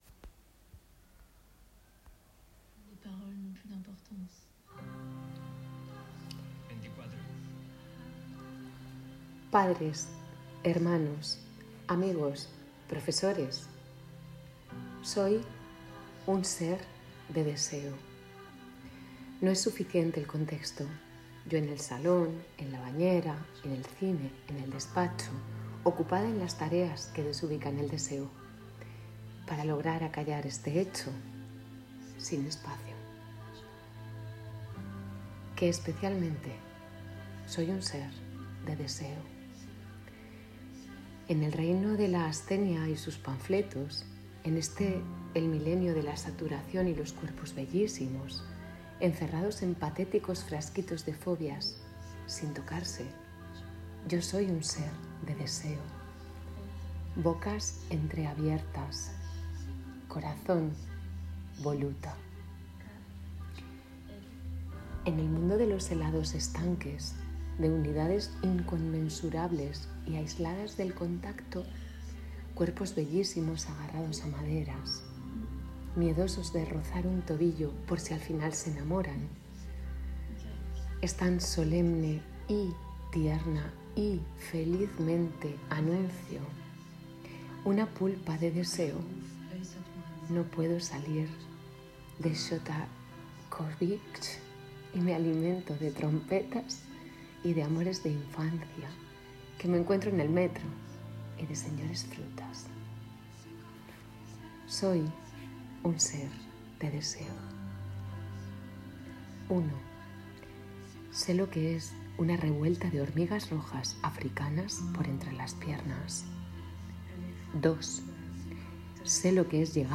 Por eso, estas palabras de aMor, que elegí y grabé días antes de la daña.
Y para ti que lo dices como los ángeles, sigue ahí, ayudando.